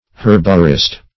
Herborist \Her"bo*rist\, n.
herborist.mp3